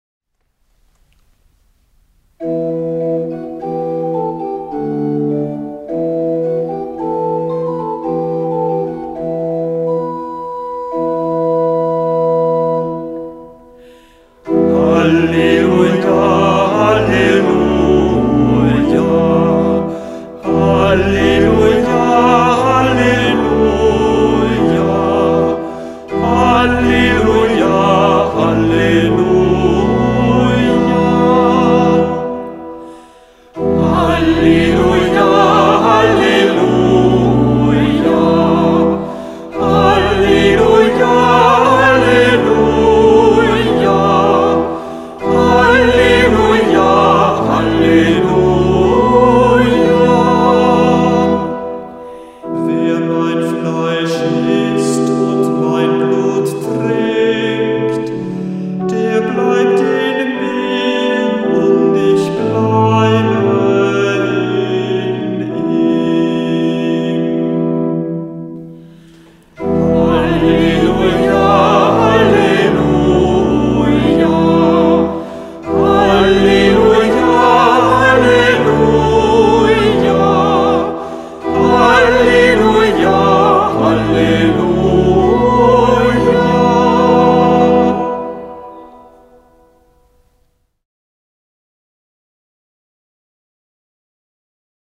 Kantor der Verse